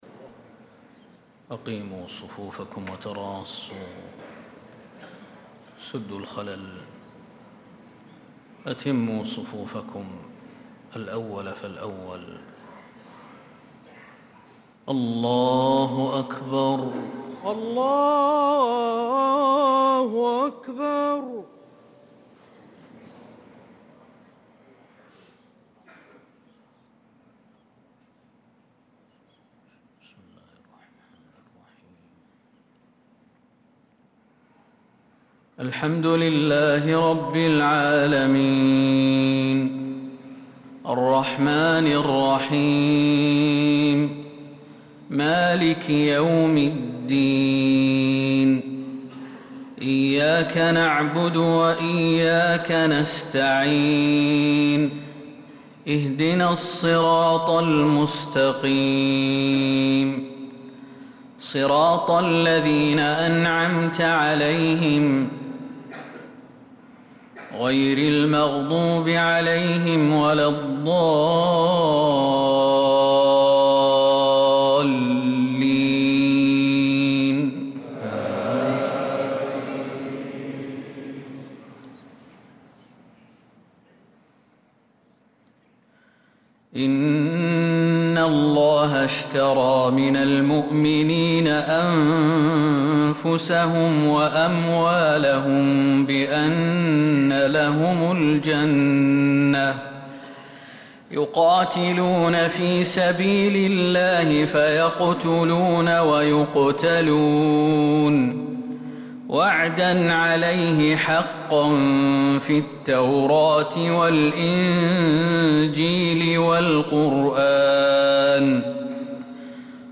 صلاة الفجر للقارئ خالد المهنا 5 جمادي الأول 1446 هـ
تِلَاوَات الْحَرَمَيْن .